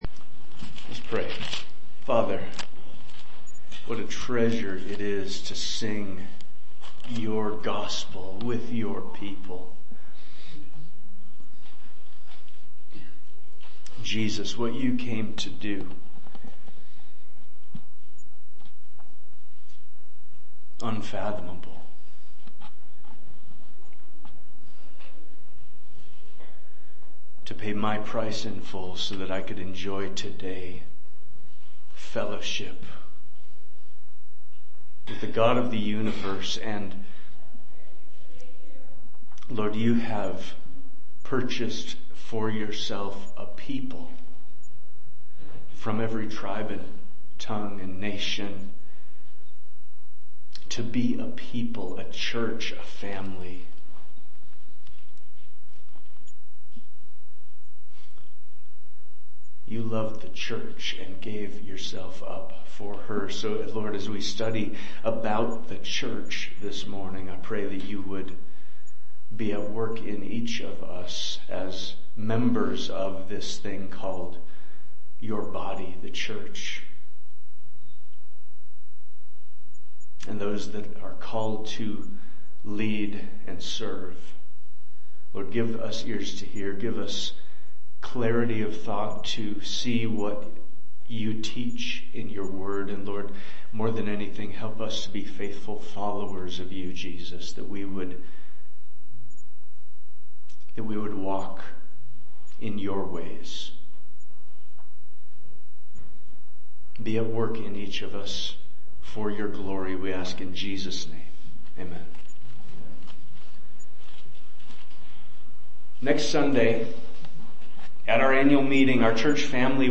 Preaching from the Pulpit of Ephraim Church of the Bible